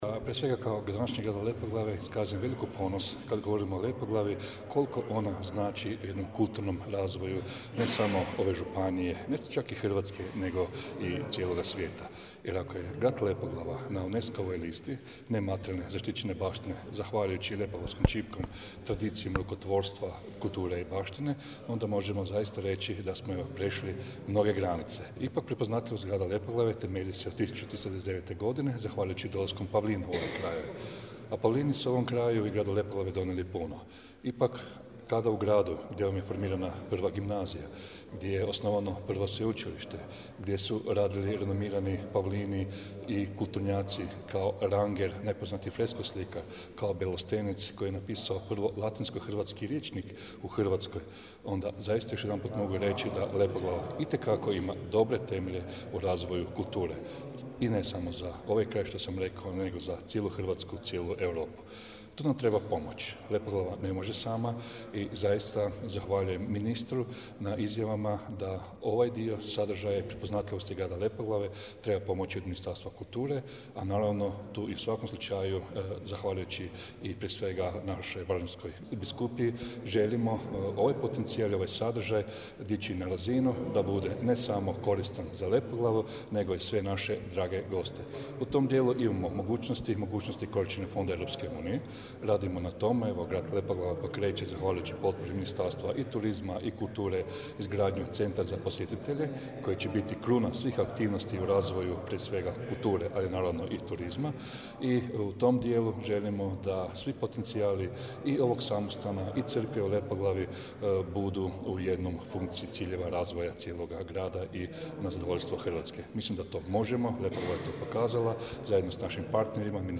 Izjava gradonačelnika Marijana Škvarića